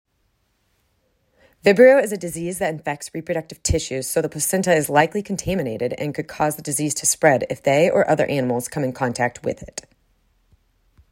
Stern_v2.mp3